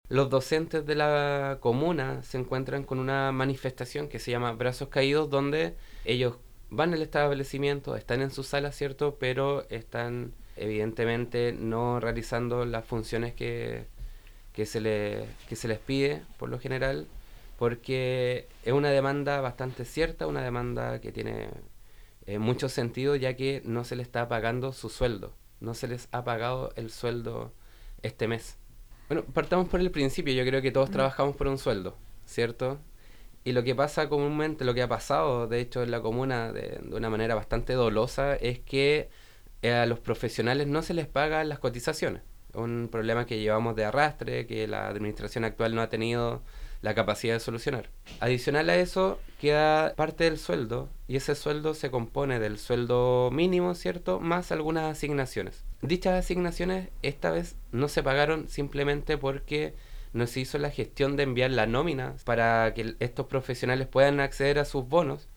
Durante la jornada los profesores se mantuvieron en las aulas pero no realizaron sus actividades cotidianas, en cuanto a las asignaciones que corresponden por el trabajo docente, estas no fueron tramitadas a tiempo por la gestión administrativa sanpablina, como lo explicó el concejal Fabián Cortez.